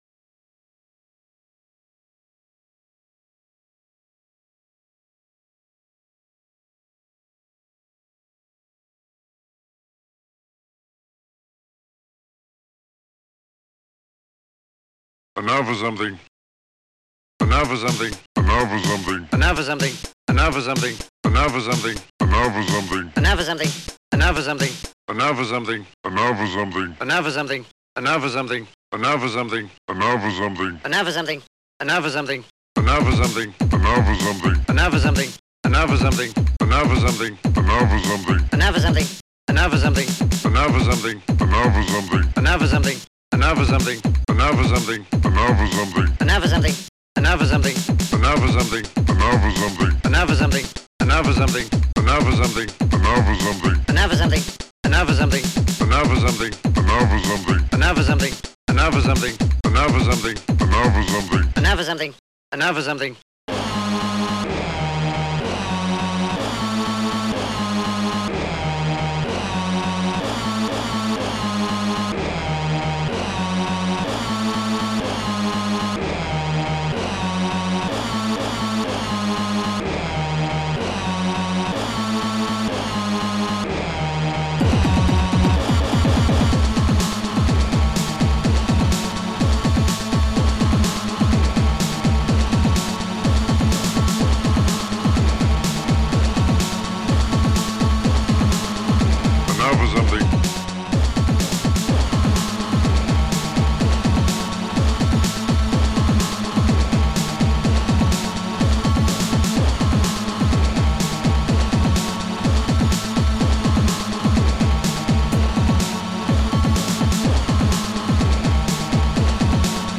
Protracker Module  |  1992-11-11  |  57KB  |  2 channels  |  44,100 sample rate  |  4 minutes, 53 seconds
Protracker and family
B_H_DRUM.AVR
SNARE10.SPL
SNARE.SPL
drum.mp3